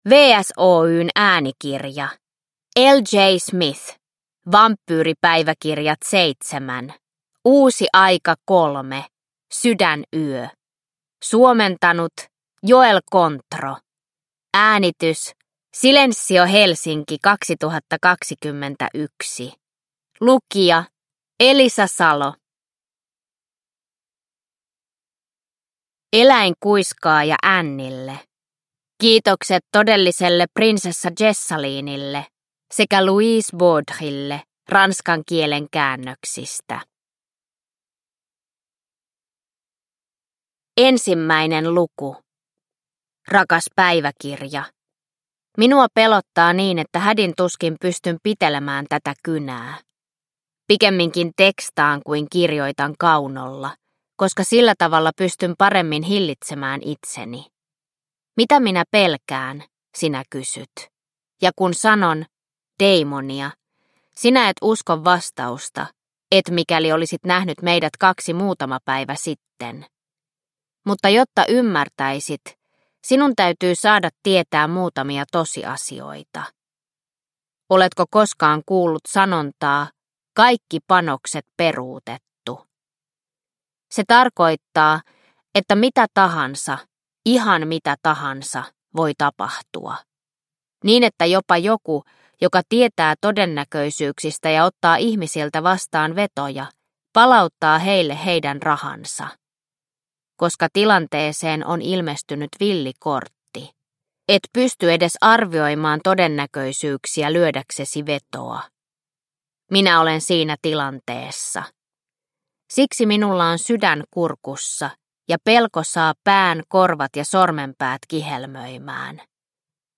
Uusi aika: Sydänyö – Ljudbok – Laddas ner